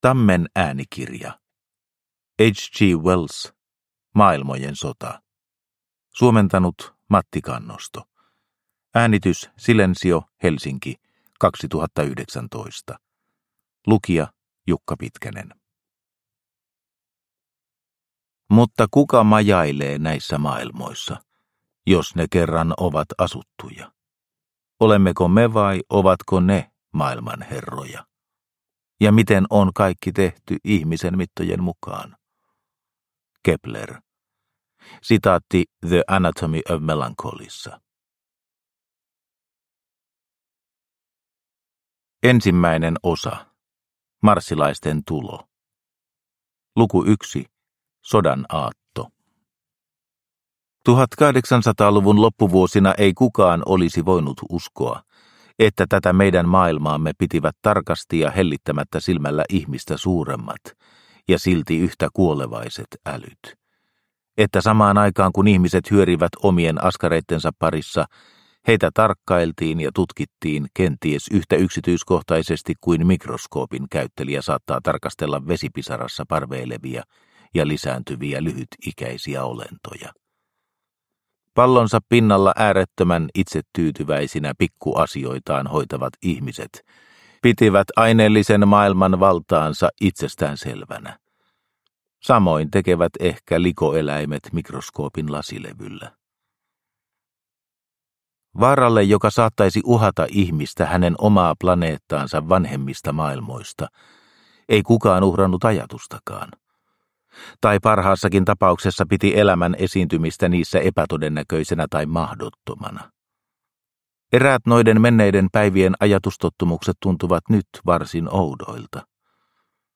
Maailmojen sota – Ljudbok – Laddas ner